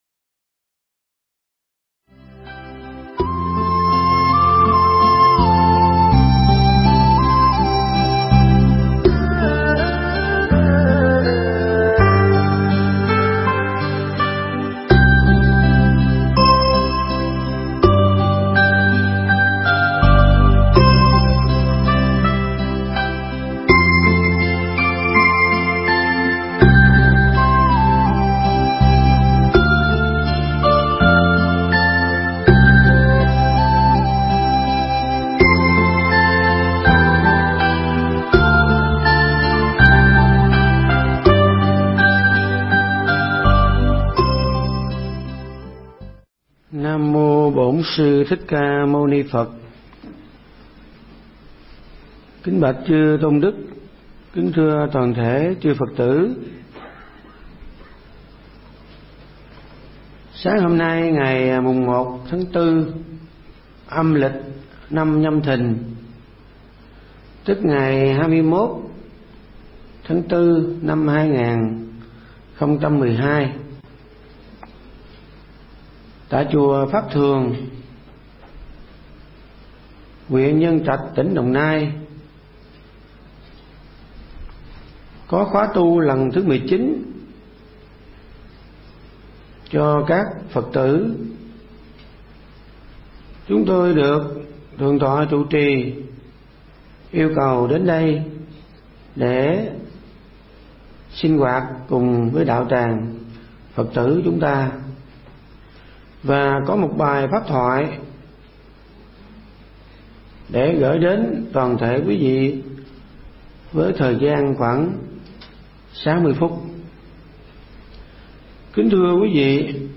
Nghe Mp3 thuyết pháp Tại Sao Tôi Phải Tu